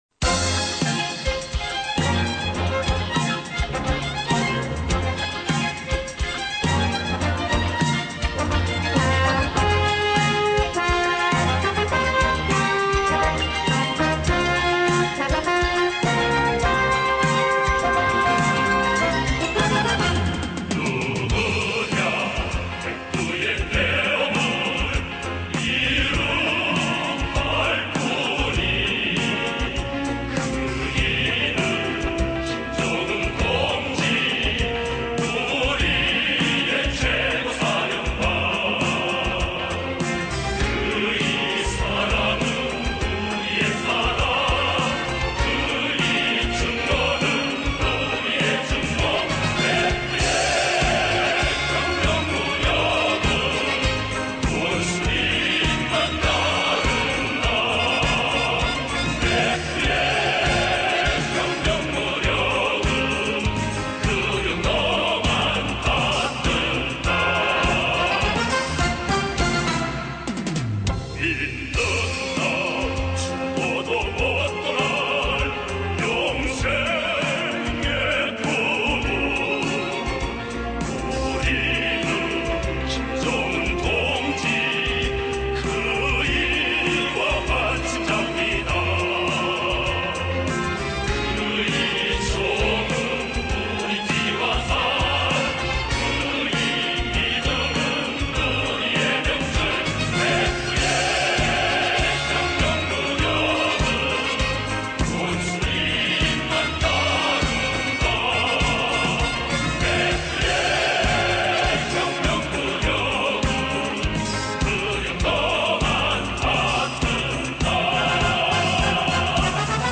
Запись с телевидения КНДР